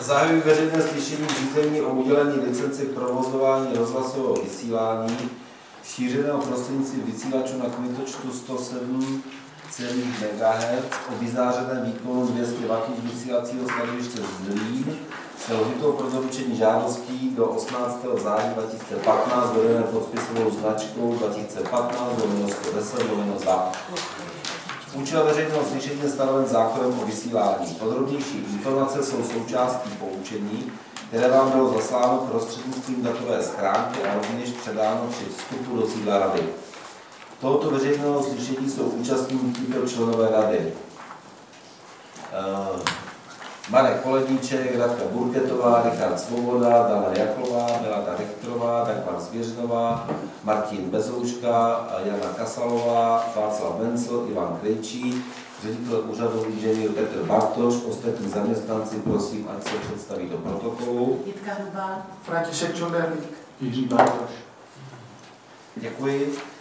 Veřejné slyšení v řízení o udělení licence k provozování rozhlasového vysílání šířeného prostřednictvím vysílačů na kmitočtu 107,0 MHz o vyzářeném výkonu 200 W z vysílacího stanoviště Zlín
Veřejné slyšení se bude konat v úterý 1. prosince 2015 v sídle Rady pro rozhlasové a televizní vysílání, Škrétova 6/44, 120 00 Praha-Vinohrady.